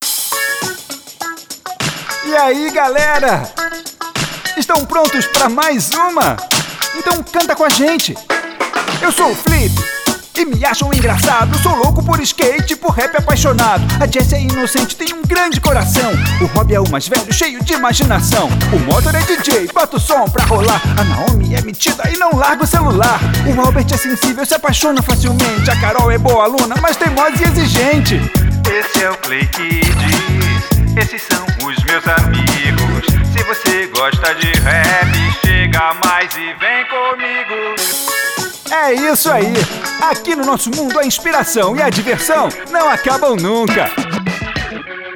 Masculino
Voz Jovem 00:45
• Tenho voz leve e versátil, e interpretação mais despojada.